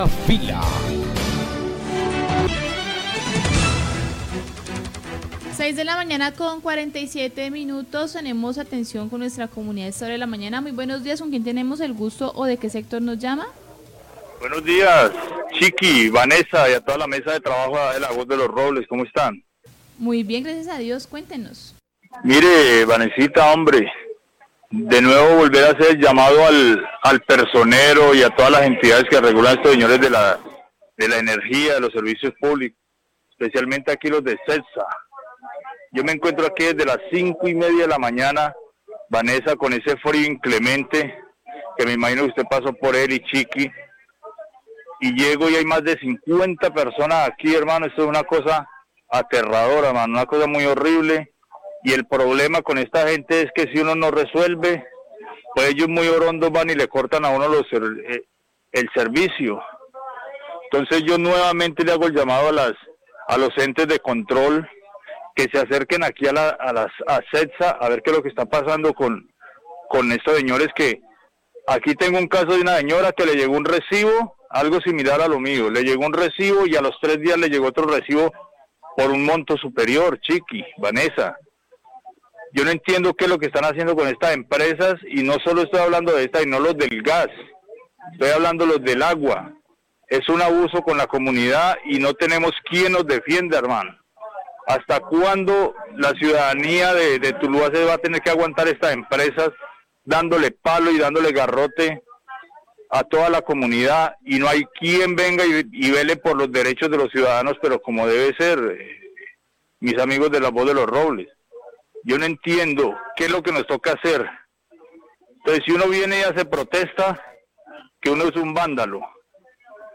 Oyente se queja por largas filas para reclamar por irregularidades en el cobro del servicio de energía, los Robles, 648am
Radio